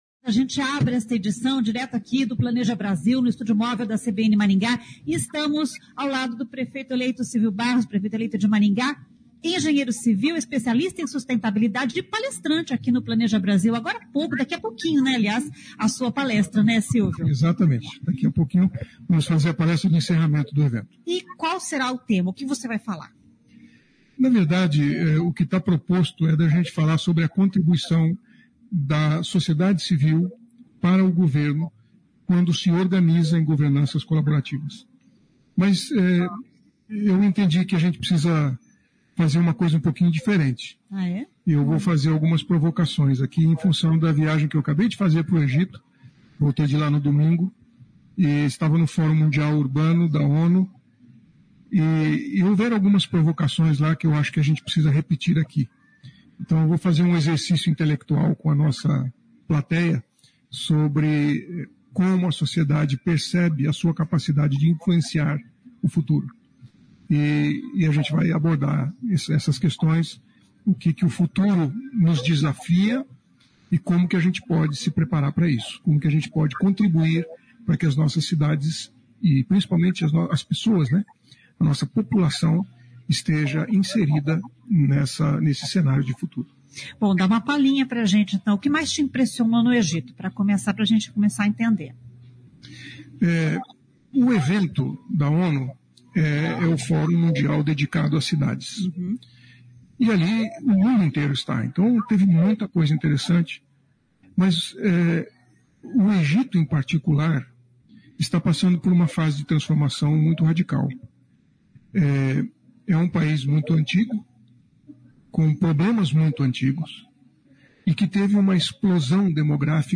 O prefeito eleito Silvio Barros esteve presente no evento Planeja Brasil e trouxe algumas "provocações" durante a palestra.